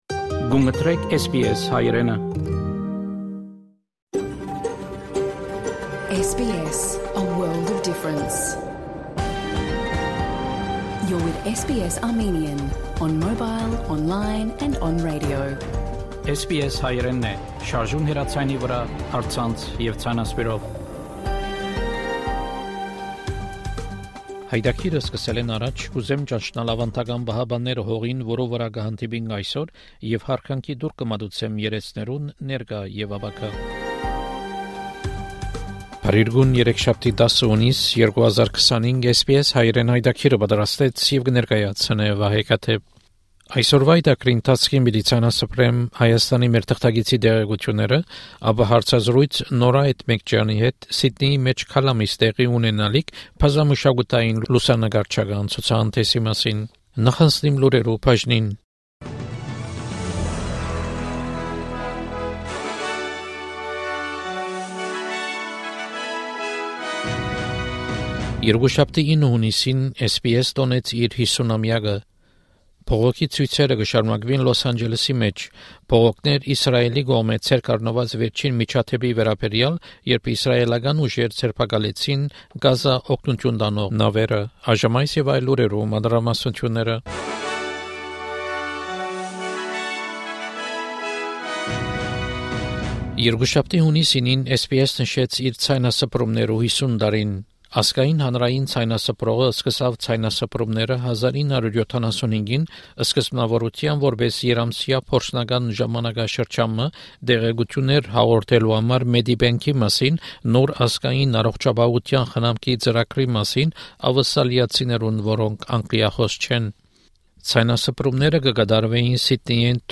SBS Armenian news bulletin from 10 June 2025 program.